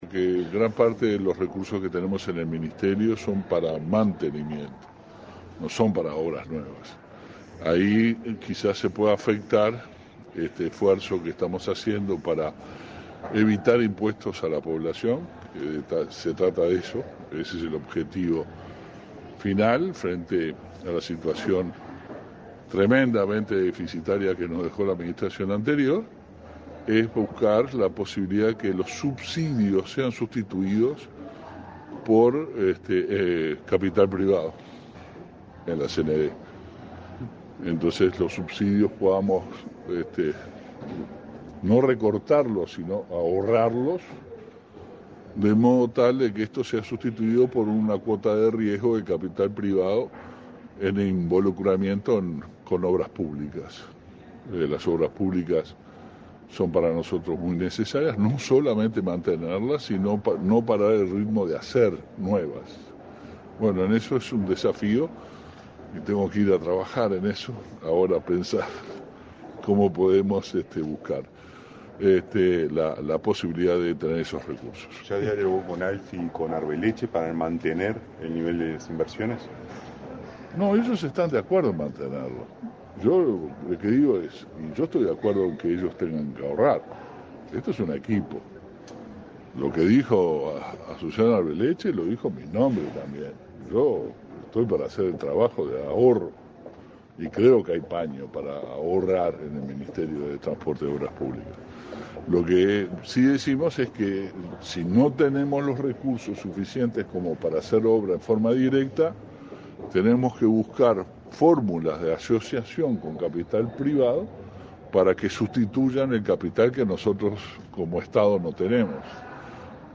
El ministro de Transporte y Obras Públicas, Luis Alberto Heber, dijo a la prensa que la unidad conformada por funcionarios profesionales de esa cartera realizará la principal auditoría, referida al estado de las rutas de todo el país. “Si no tenemos los recursos suficientes para hacer obras en forma directa, debemos buscar fórmulas de asociación con capital privado”, anticipó el jerarca.